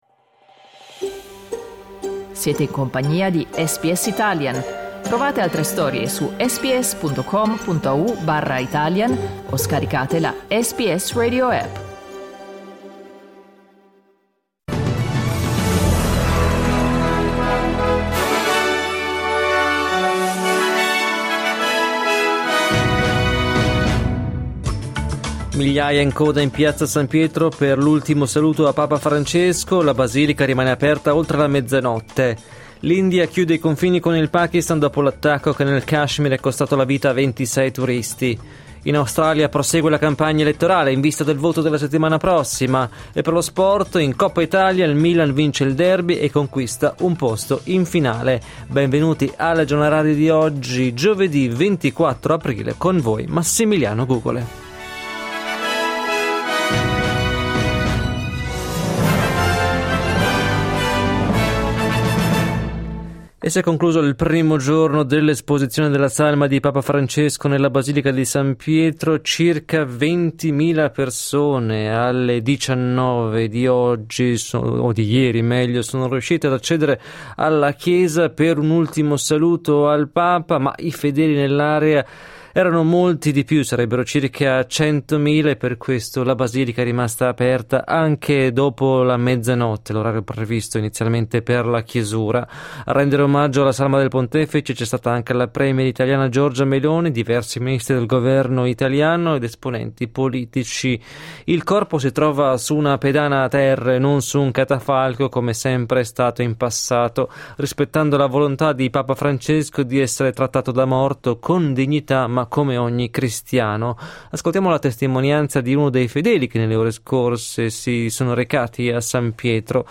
Giornale radio giovedì 24 aprile 2025
Il notiziario di SBS in italiano.